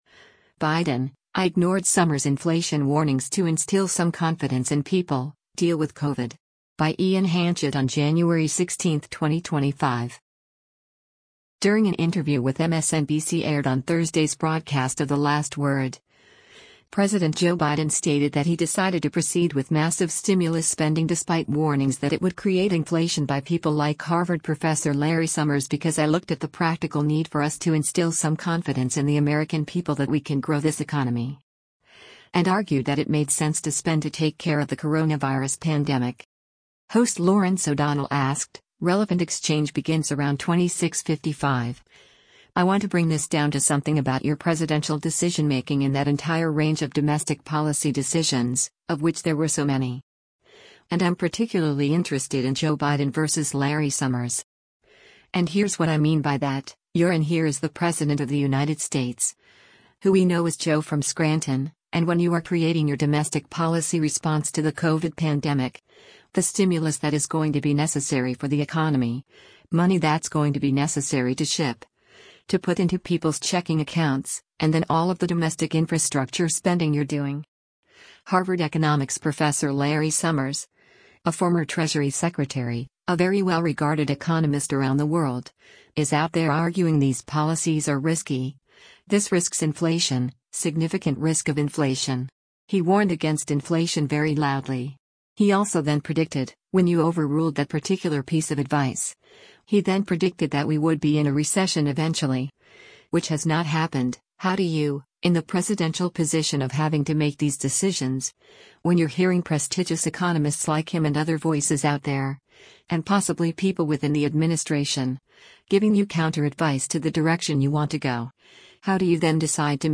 During an interview with MSNBC aired on Thursday’s broadcast of “The Last Word,” President Joe Biden stated that he decided to proceed with massive stimulus spending despite warnings that it would create inflation by people like Harvard Professor Larry Summers because “I looked at the practical need for us to instill some confidence in the American people that we can grow this economy.”